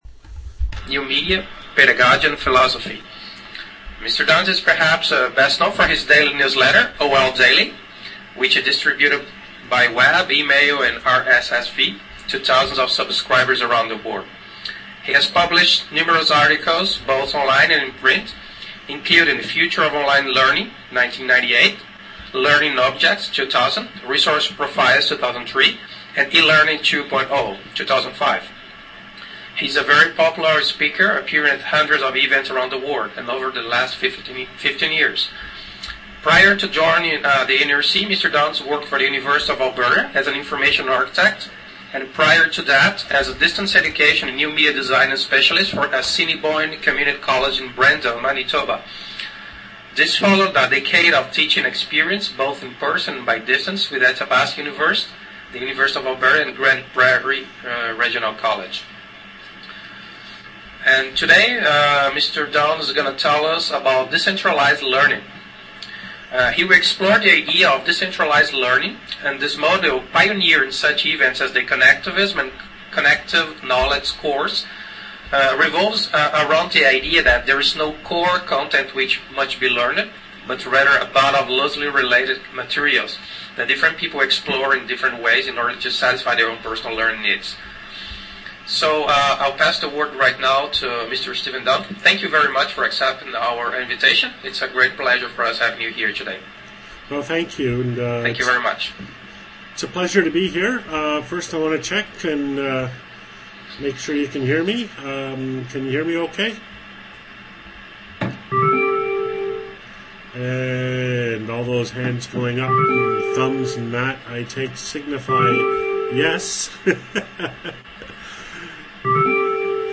In this online presentation for APOP (Association for the Educational Application of Computer Technology at the Post-Secondary Level-Montréal) I discuss the concept of decentralized learning. I begin with the physical model, as instantiated in the Connectivism course, then outline the epistemology of knowledge production, then describe the pedagogy of personal learning, and conclude with success factors. A long Q&A in which we discuss numerous issues follows.